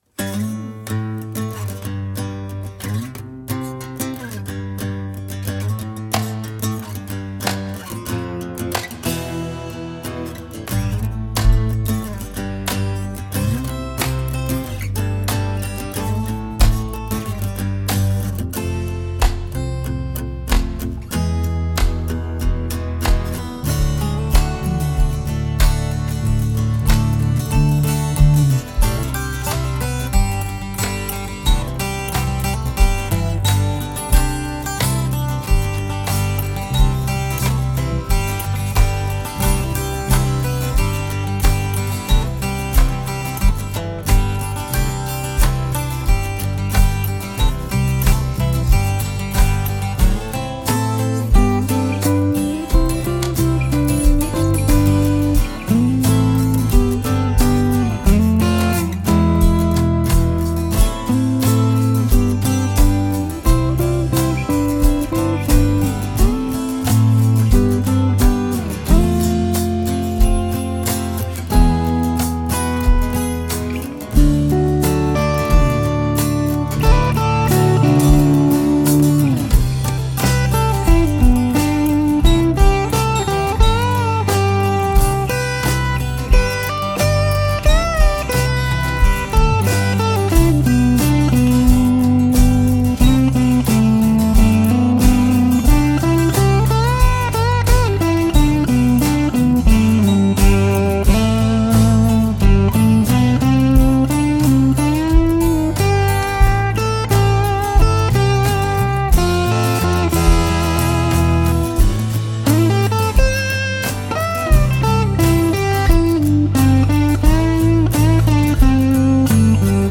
solo overdubbed multitrack recordings